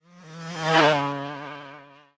car8.ogg